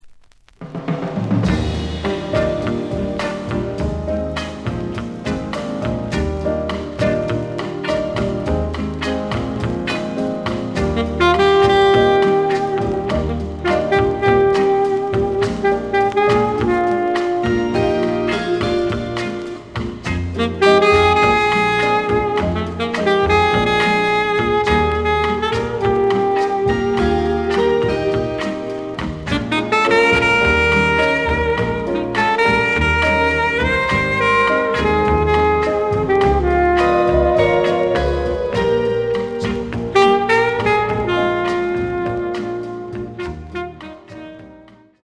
The audio sample is recorded from the actual item.
●Genre: Cool Jazz